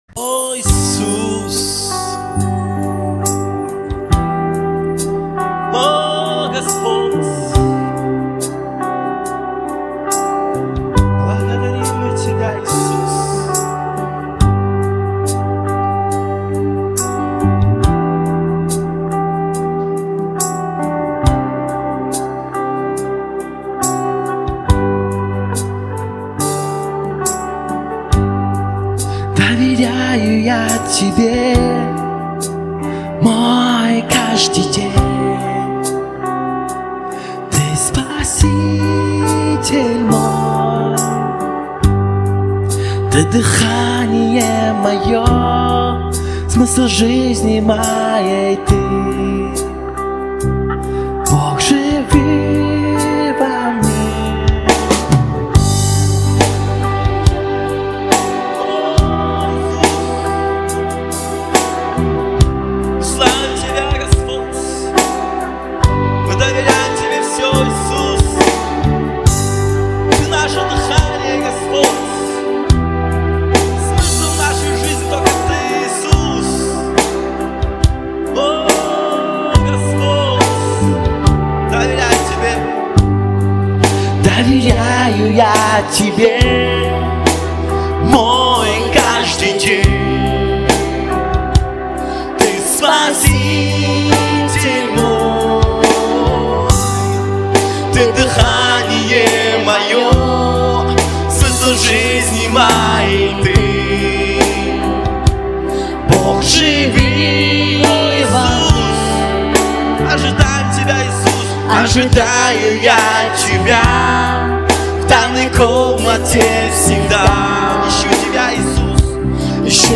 249 просмотров 51 прослушиваний 1 скачиваний BPM: 70